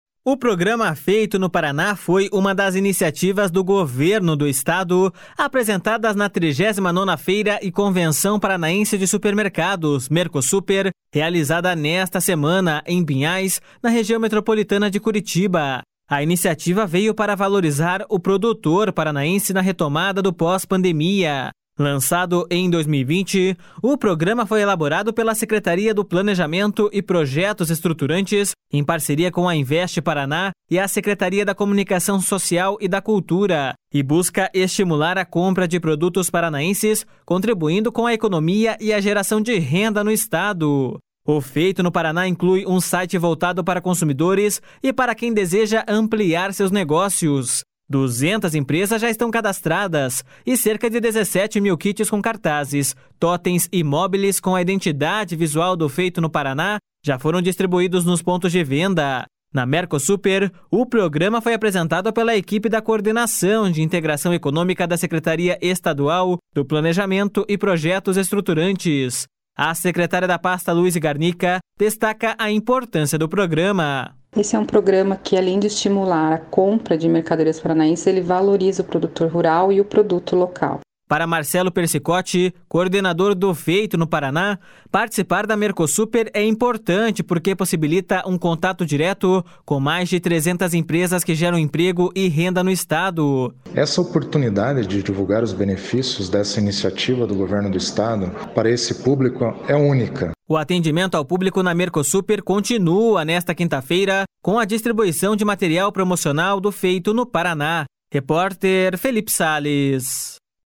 A secretária da pasta, Louise Garnica, destaca a importância do programa.// SONORA LOUISE GARNICA.//